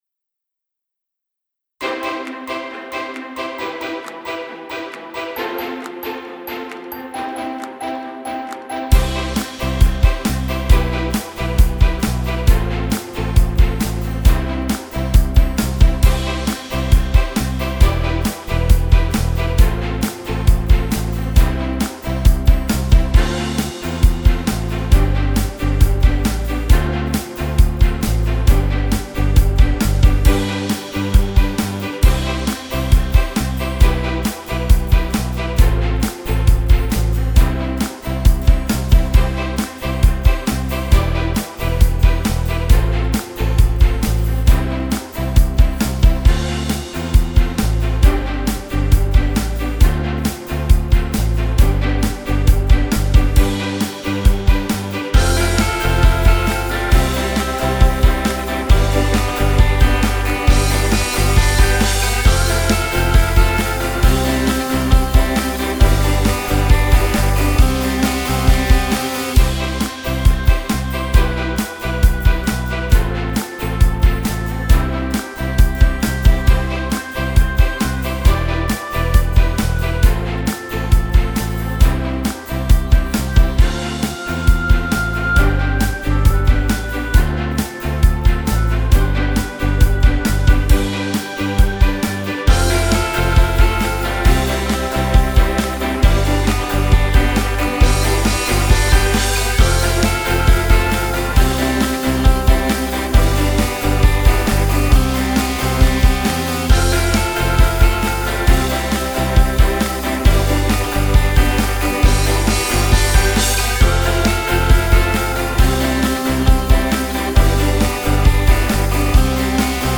Base Musicale